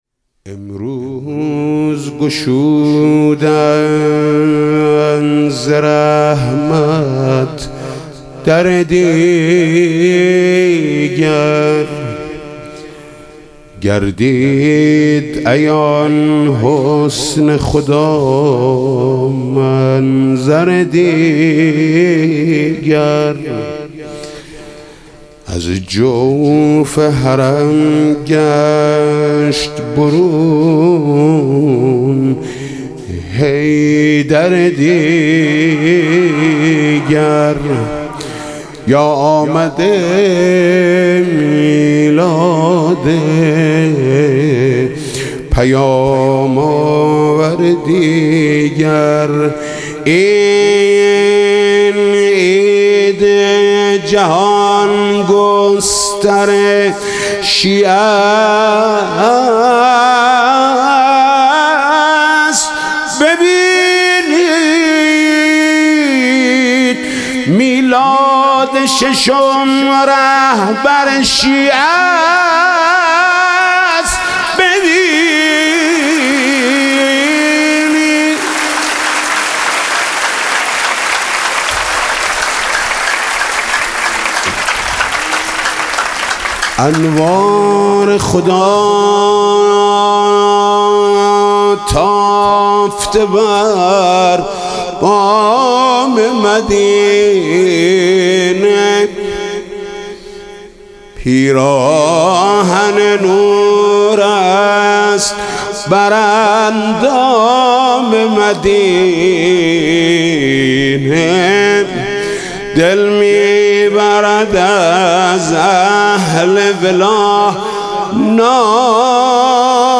مدح میلاد پیامبر و امام صادق (علیهم السلام)